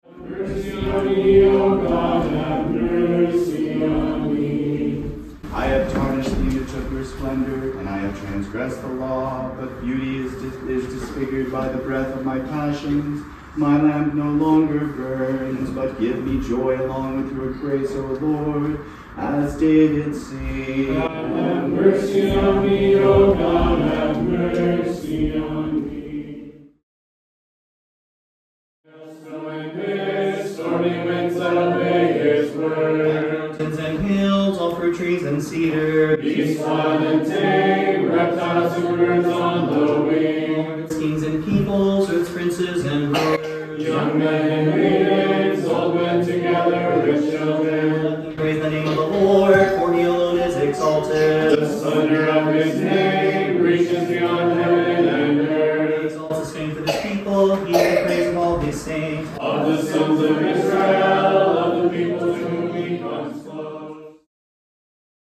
Vespers was three hours long, as it went through all of Salvation History. In the litanies that night, every person compared himself to each sinner of the Old Testament and New Testament. I sang in Byzantine tones that I myself was worse than each one of those Biblical sinners.
There was sadness and hope infused into every tone in this Great Fast.